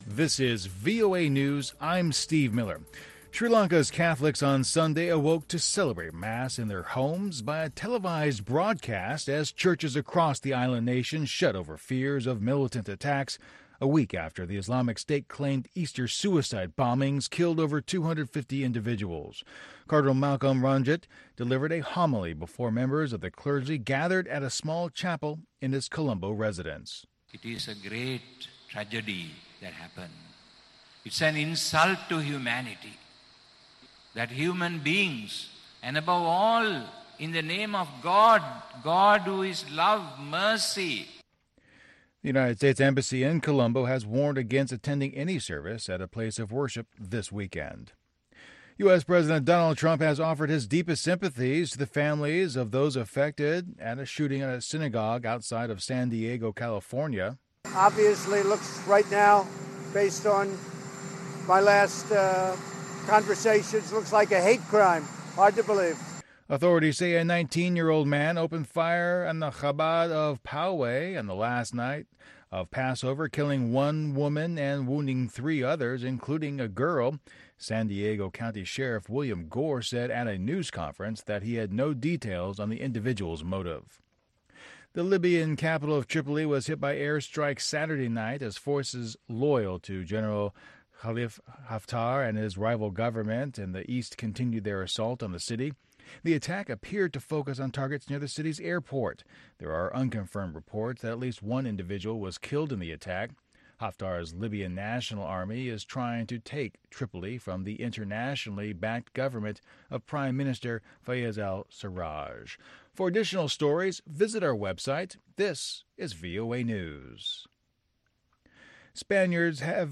Music Time in Africa is VOA’s longest running English language program. Since 1965, this award-winning program has featured pan African music that spans all genres and generations.